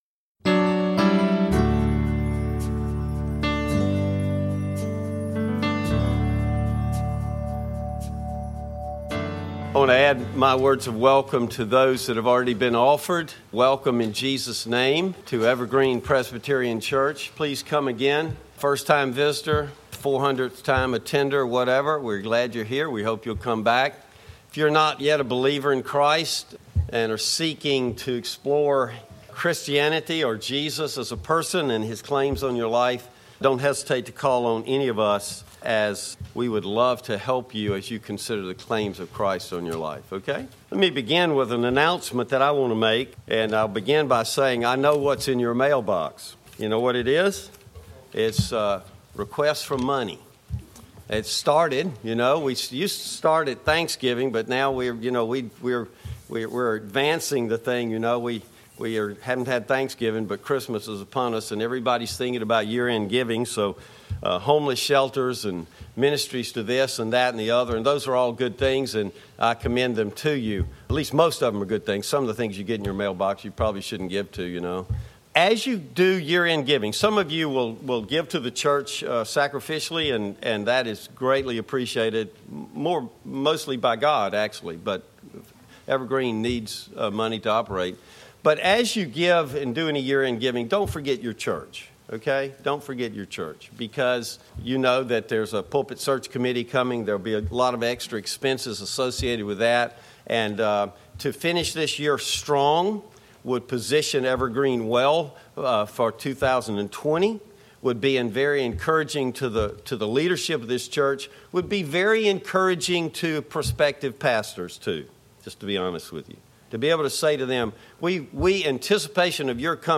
Bible Text: Deuteronomy 8:1-20 | Preacher: